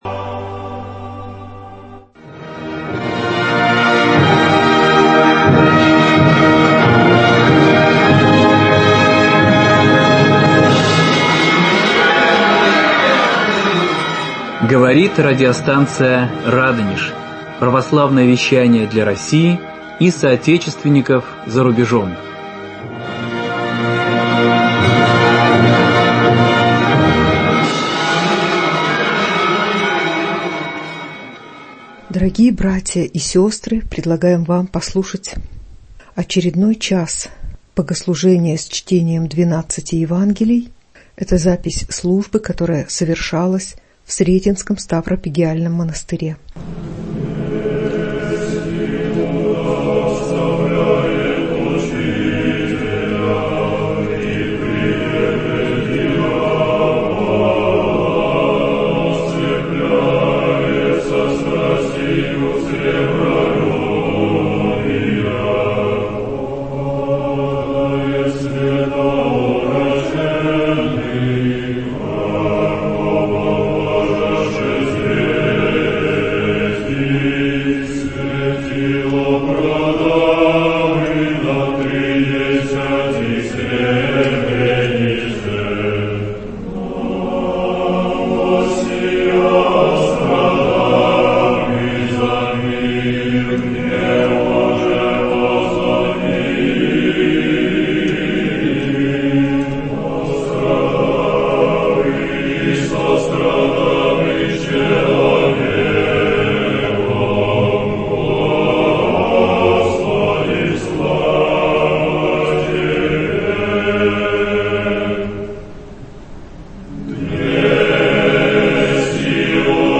Фрагменты утрени Великой пятницы с чтением 12 Евангелий в Сретенском монастыре ч.2